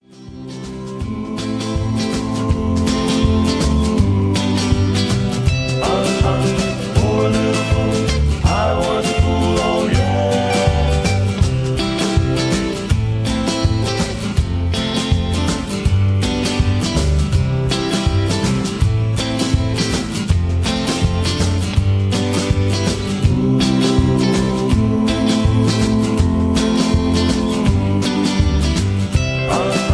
Key-C
Just Plain & Simply "GREAT MUSIC" (No Lyrics).